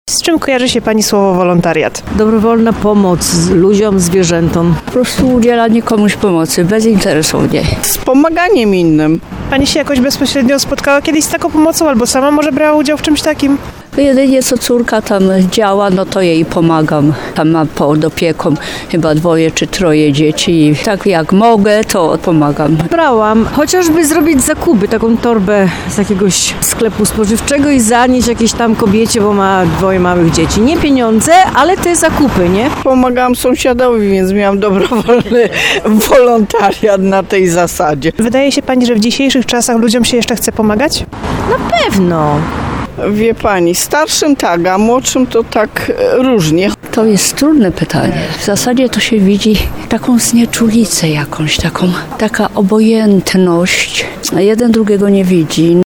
4wolontariat-sonda.mp3